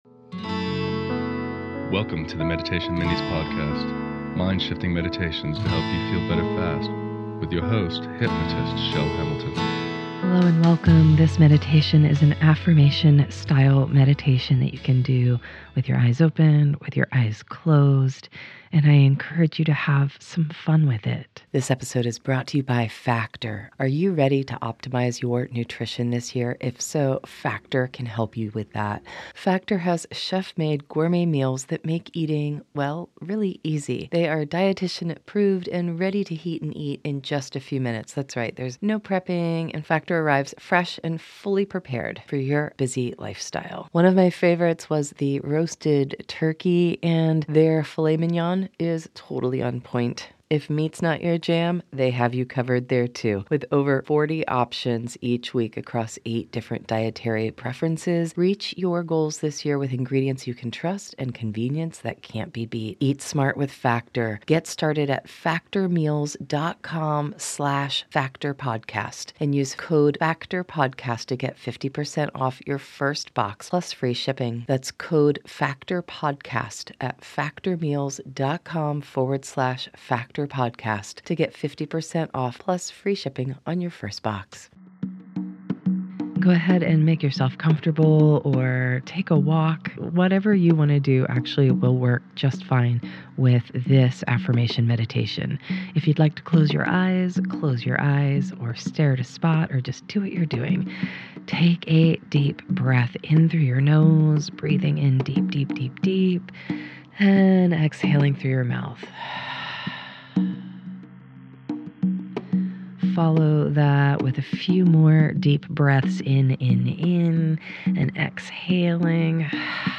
Here's an affirmation style meditation you can use to create (or REcreate) your own GOOD DAY no matter what other people or situations throw at you.